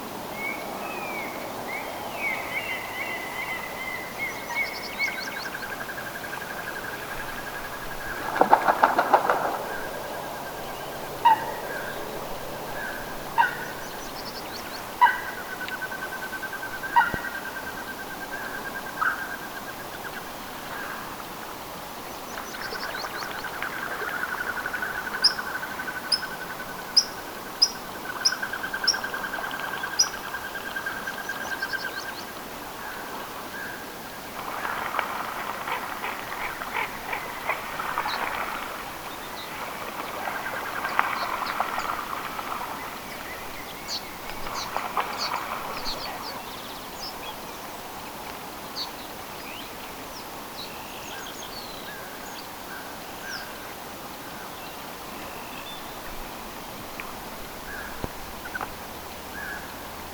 telkät lentelevät,
kilpikanan ääntelyä vähäsen
telkat_lentelevat_kilpikanan_aantelya.mp3